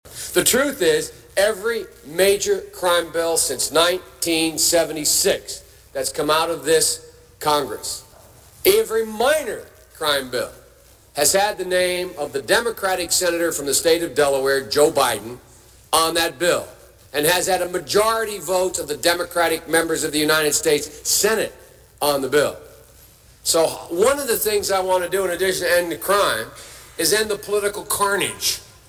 JOE BIDEN in his own words, Please Listen!!!! Lesser of two evils?